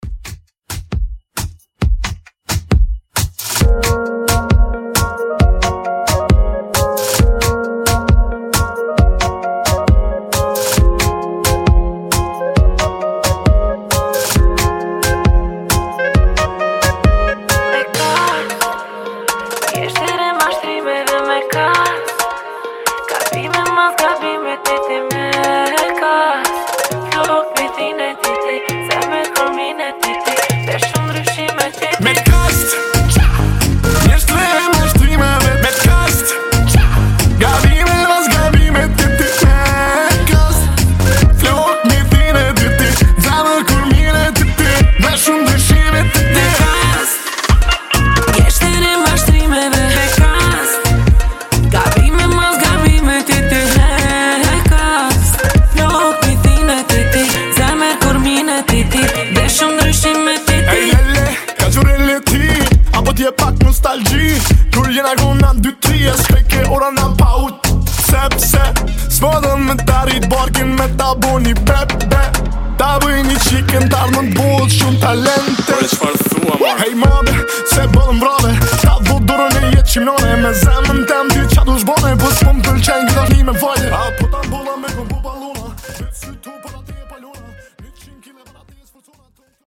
Genre: GERMAN MUSIC
Dirty BPM: 146 Time